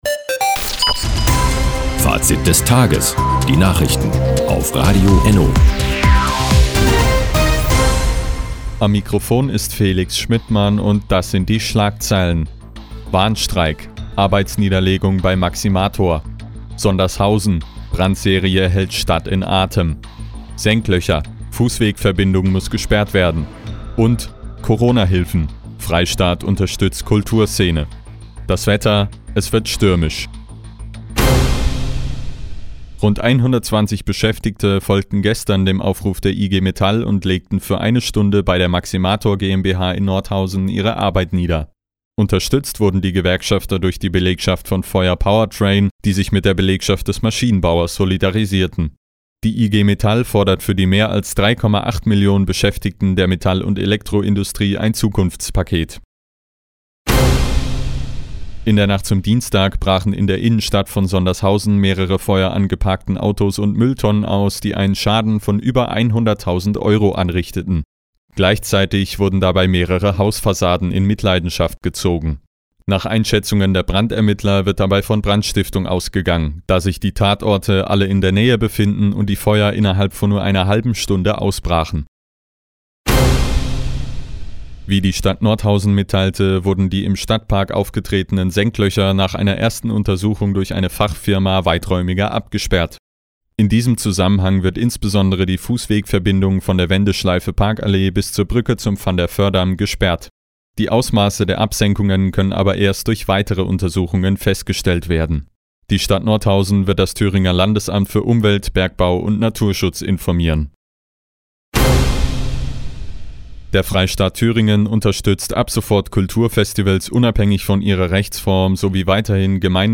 Mi, 16:30 Uhr 10.03.2021 Neues von Radio ENNO Fazit des Tages Anzeige Refinery (lang) Seit Jahren kooperieren die Nordthüringer Online-Zeitungen und das Nordhäuser Bürgerradio ENNO. Die tägliche Nachrichtensendung ist jetzt hier zu hören.